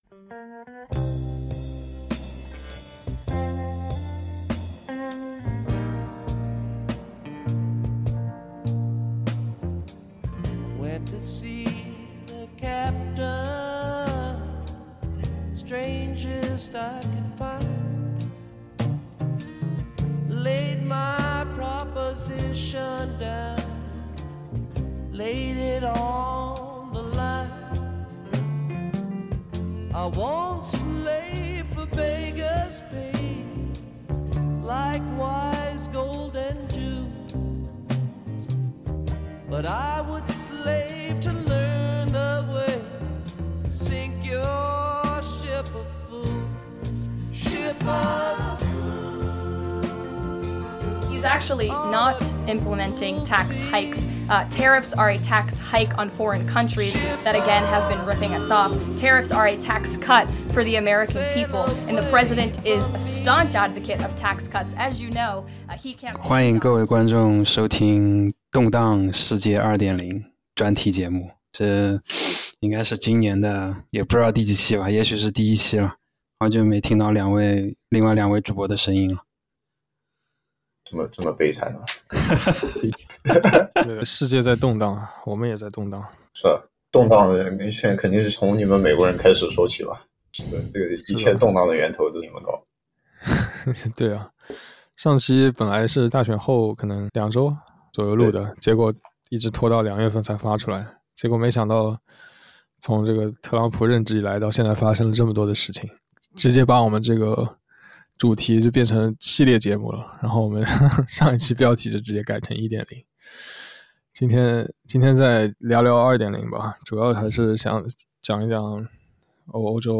**本期节目包含大量的脏话和狂暴的带有个人情感色彩的评论，如果你在收听的时候感到不适请自行关闭。